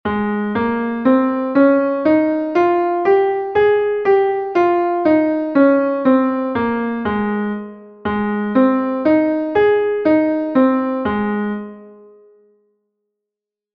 Entoación a capella
Escala e arpexio:
escala_arpegio_lab_M.mp3